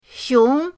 xiong2.mp3